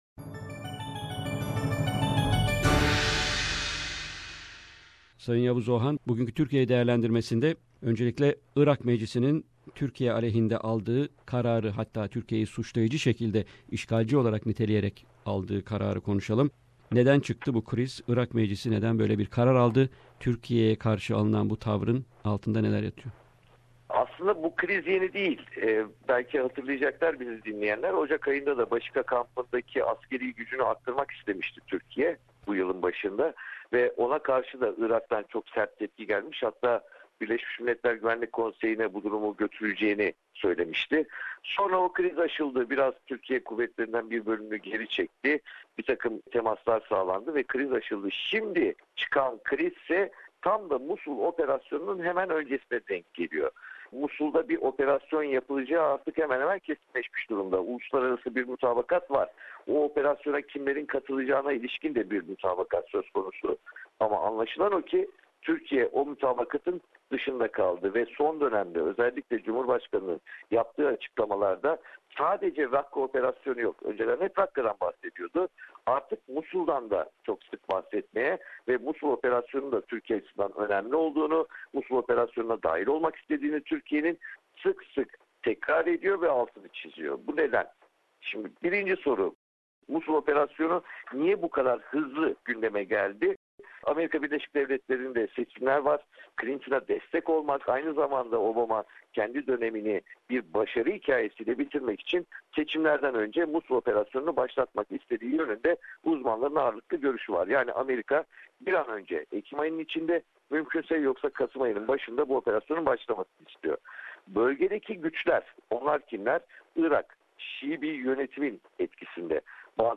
Weekly Stringer Report from Istanbul